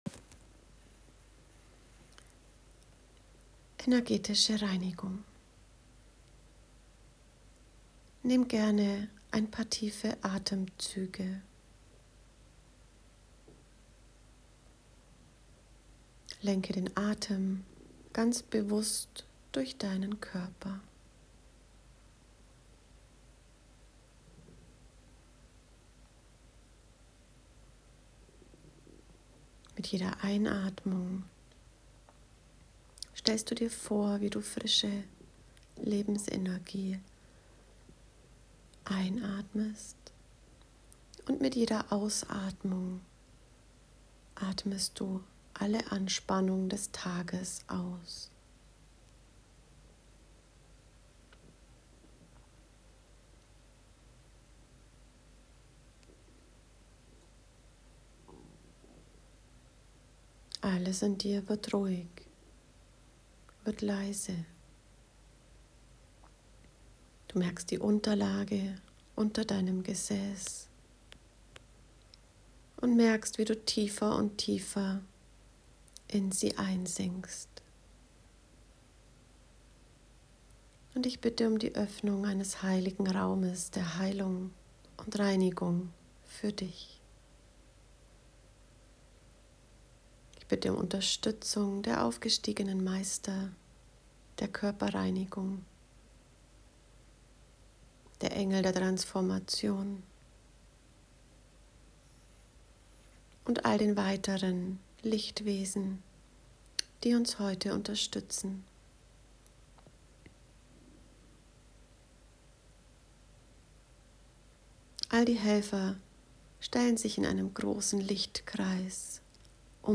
Energetische Morgen- und Abendreinigung (Audio-Meditation)